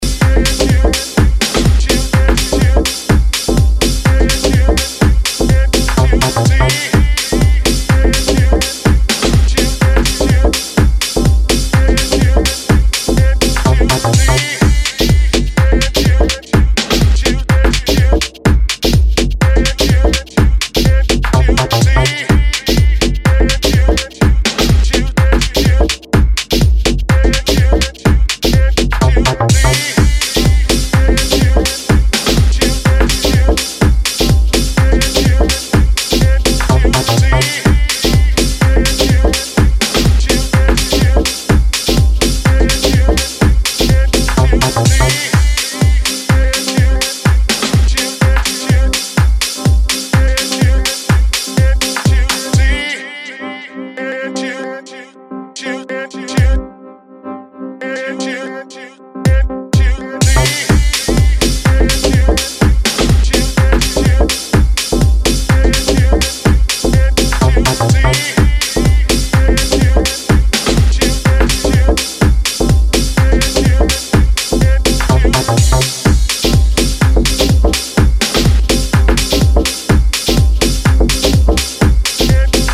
packed with soul and delivered with a weighty bottom end